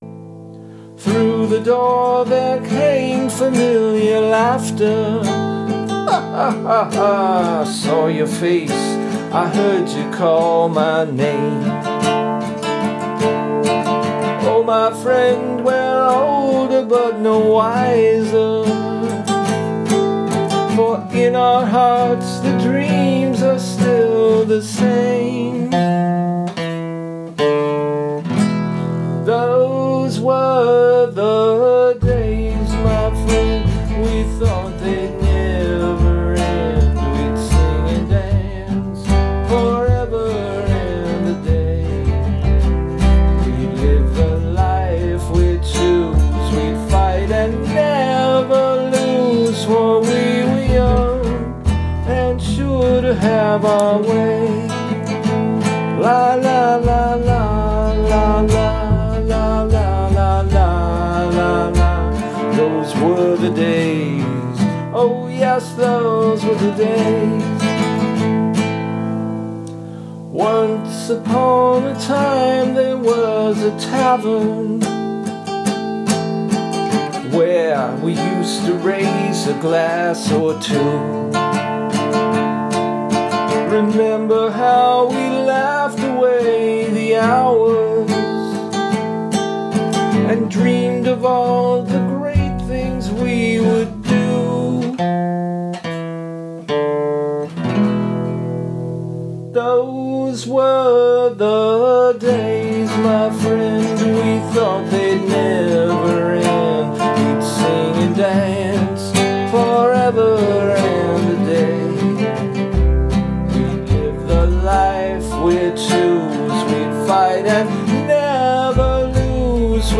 Genre: Alternative Rock.